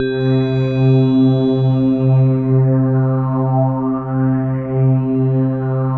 PAD FLYIN0DL.wav